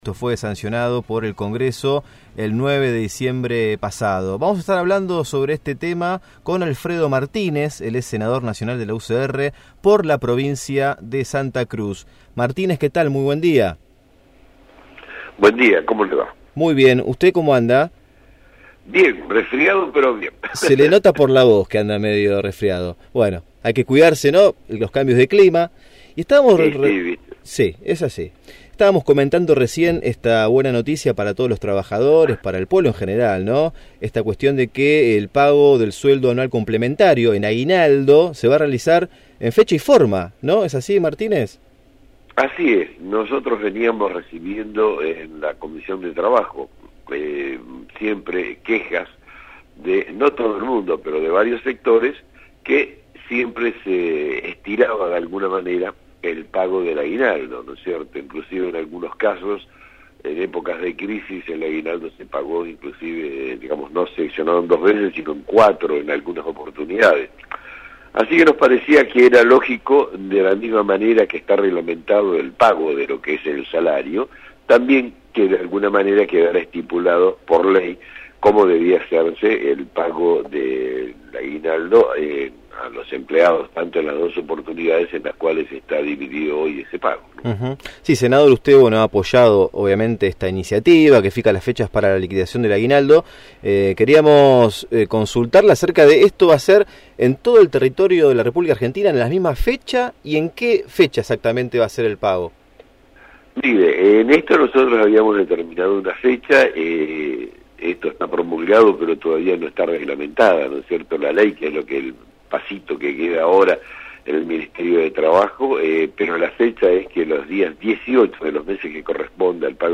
El senador nacional por Santa Cruz (UCR), Alfredo Martínez, se refirió en Radiópolis Ciudad Invadida (FM 107.5) a la promulgación de la ley que establece el 30 de junio como fecha tope para el pago de la primera cuota del sueldo anual complementario.